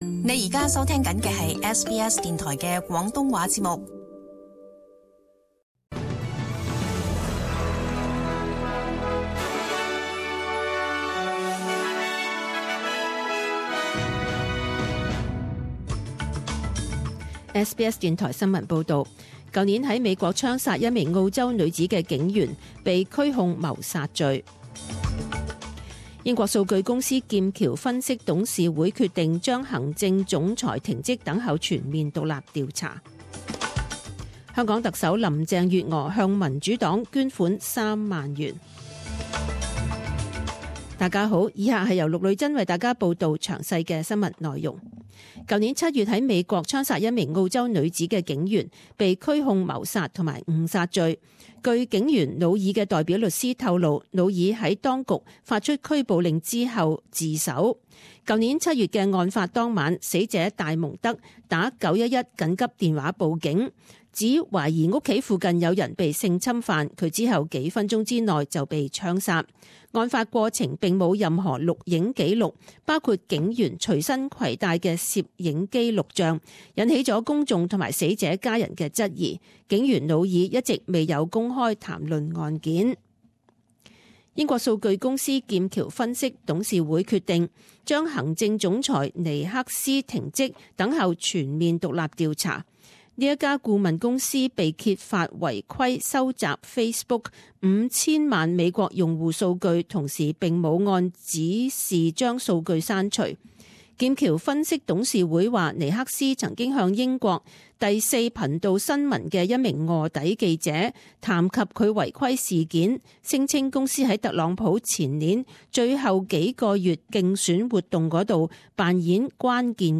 SBS中文新闻 （三月二十一日）